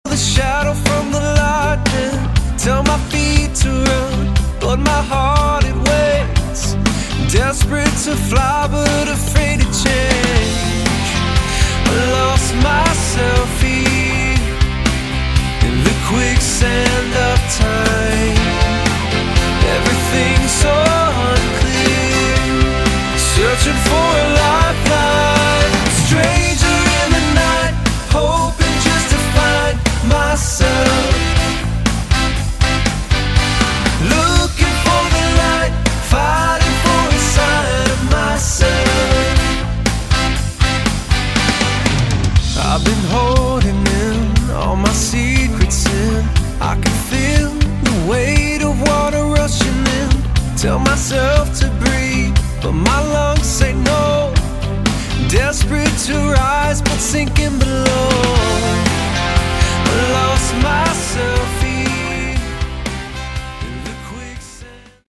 Category: Light AOR
guitars, keyboards
vocals
bass
drums